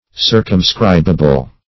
Circumscribable \Cir`cum*scrib"a*ble\, a.
circumscribable.mp3